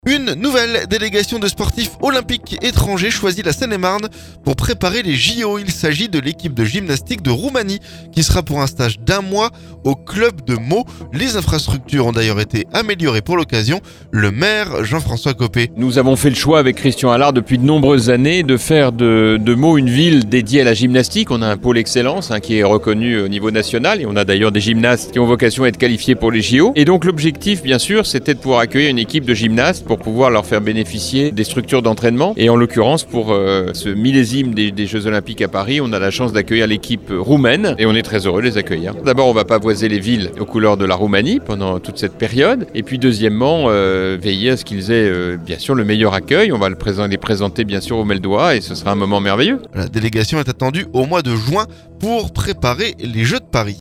Le maire, Jean-François Copé.